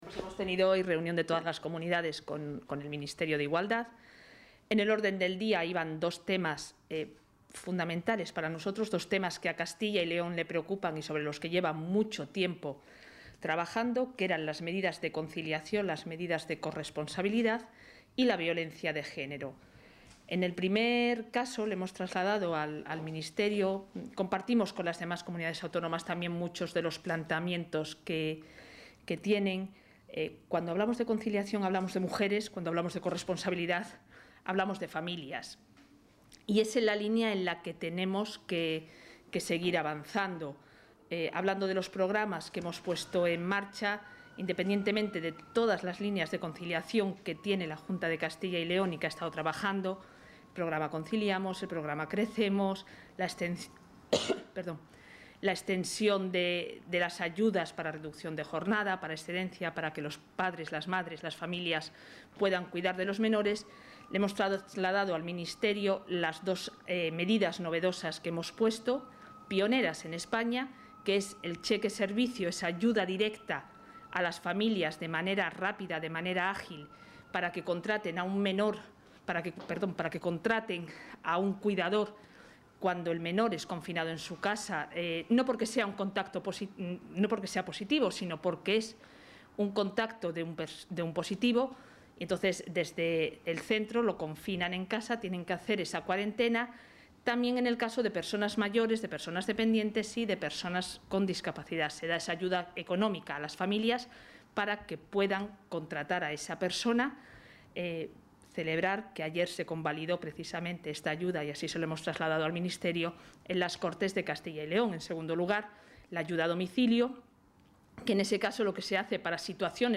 Valoración de la consejera de Familia e Igualdad de Oportunidades.